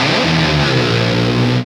Index of /90_sSampleCDs/Roland L-CDX-01/GTR_GTR FX/GTR_Gtr Hits 1
GTR DIVE 07R.wav